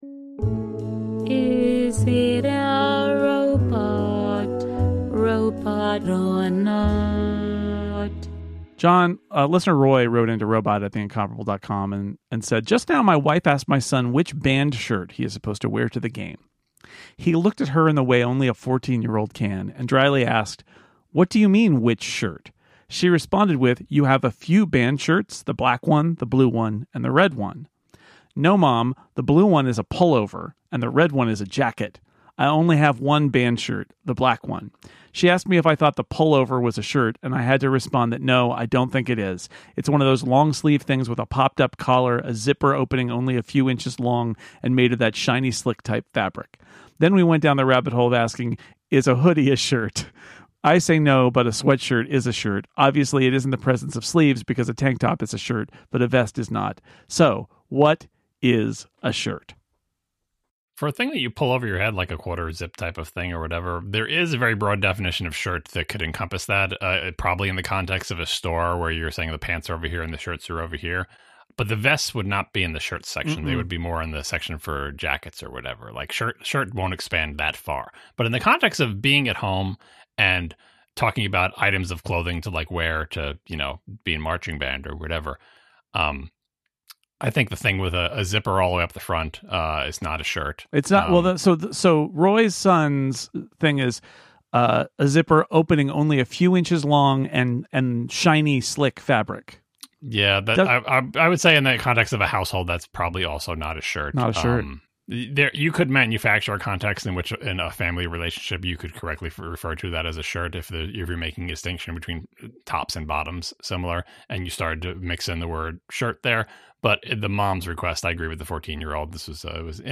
Hosts John Siracusa and Jason Snell